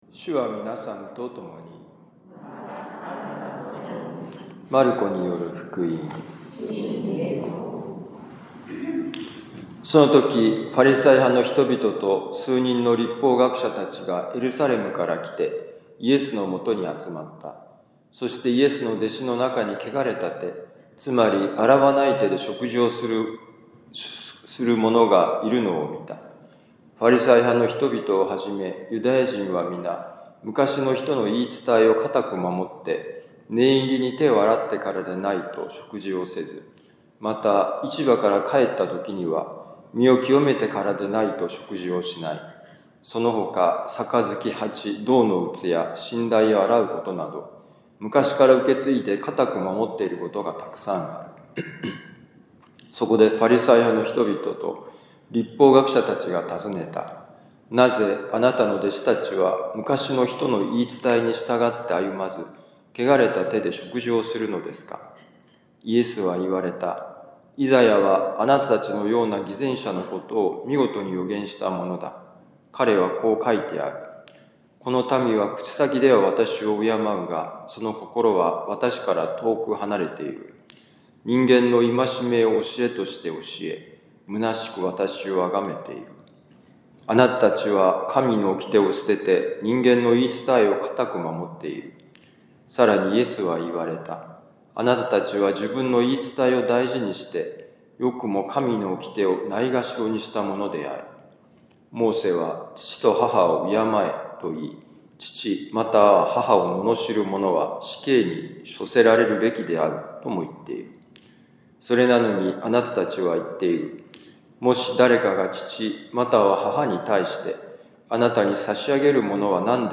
マルコ福音書７章1-13節「よいものに戻る」2025年2月11日いやしのミサ六甲カトリック教会